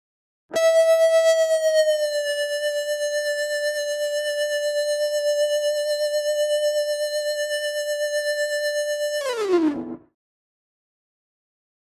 Rock Guitar Distorted FX 4 - Long Sustain Tone 1